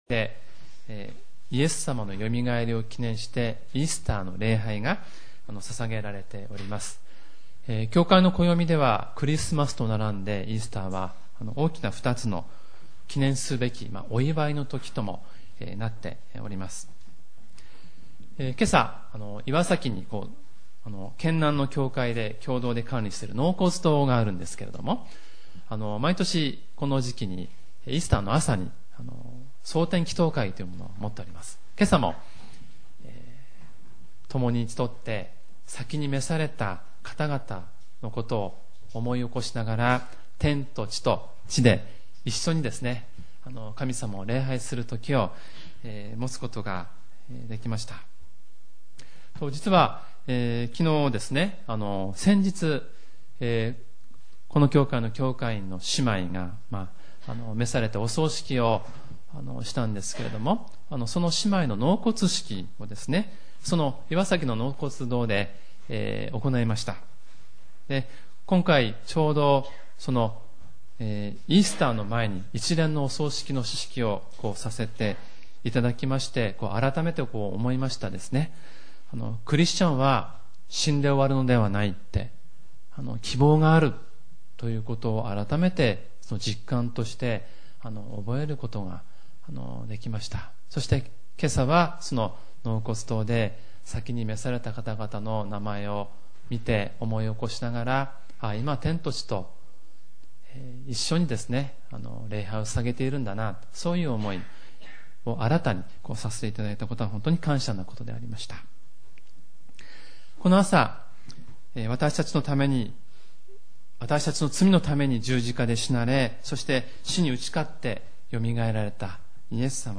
●主日礼拝メッセージ（MP３ファイル、赤文字をクリックするとメッセージが聞けます）